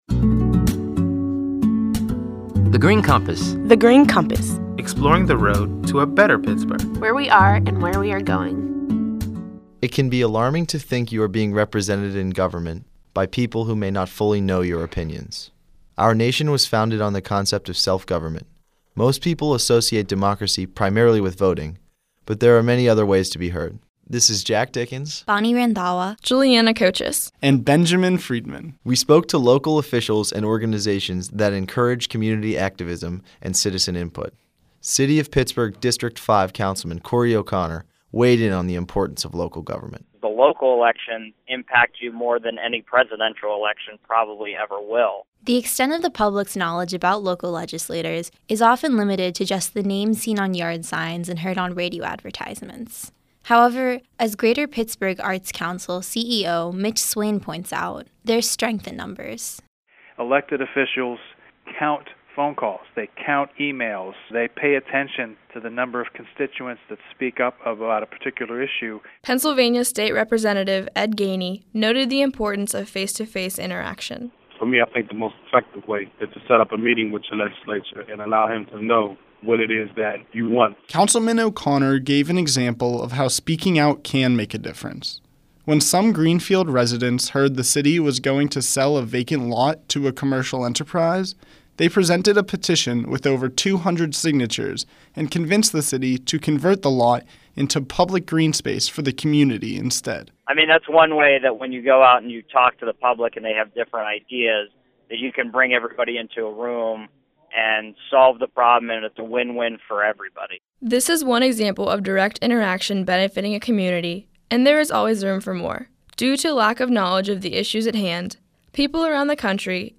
In July 2014, thirty-two recent high-school graduates created these radio features while serving as Summer Interns at The Heinz Endowments.